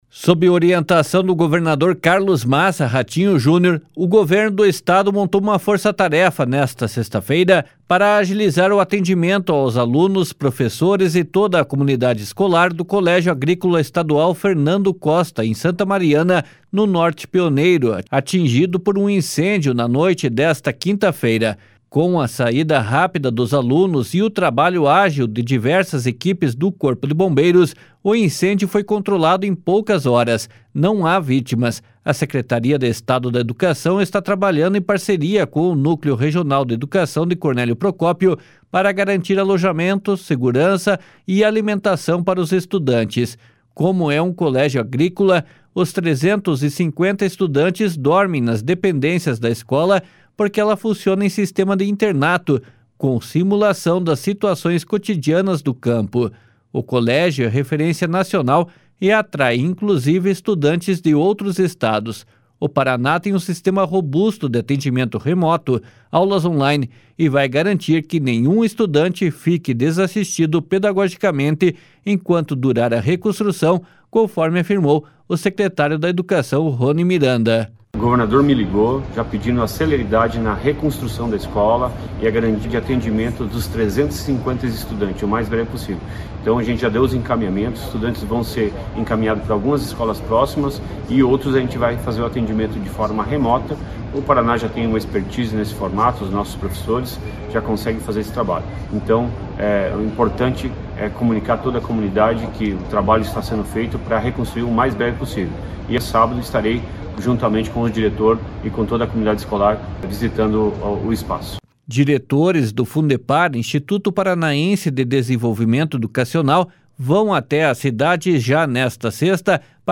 //SONORA RONI MIRANDA//